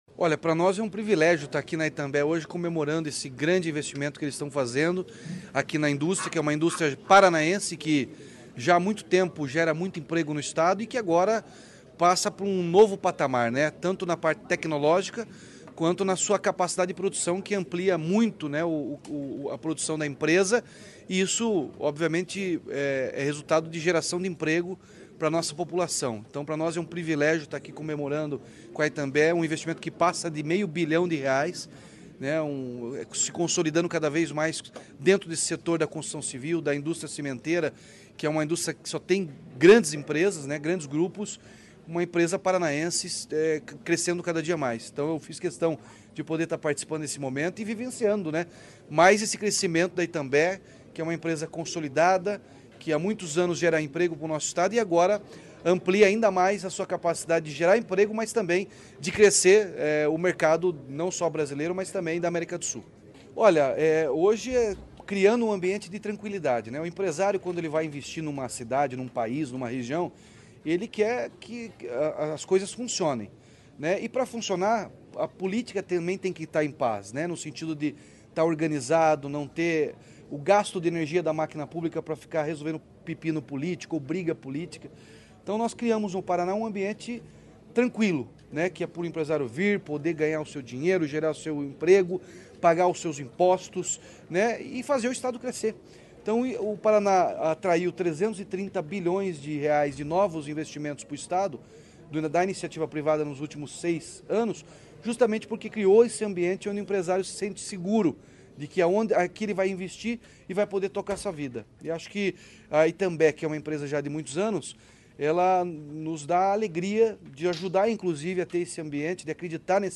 Sonora do governador Ratinho Junior sobre a inauguração de um novo forno da fábrica de cimento da Itambé